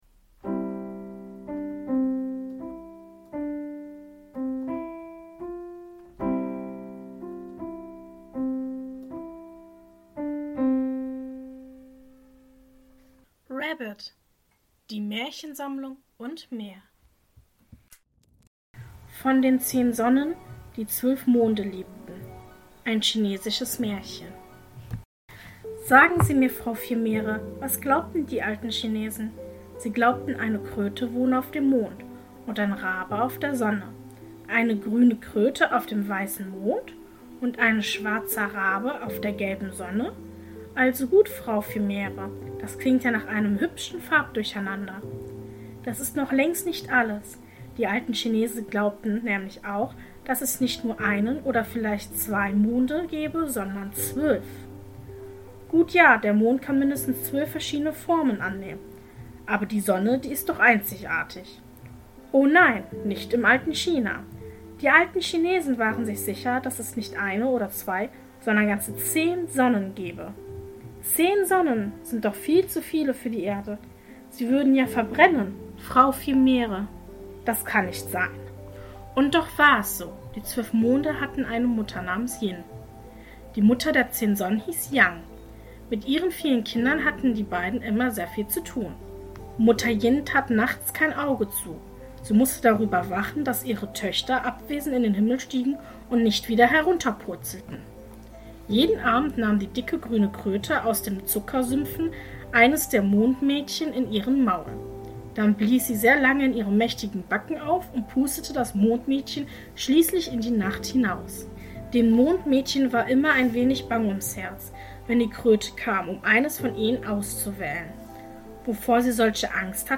In der heutigen Folge lese ich Folgendes vor: 1. Ein chinesisches Märchen: Von den zehn Sonnen die zwölf Monde liebten Mehr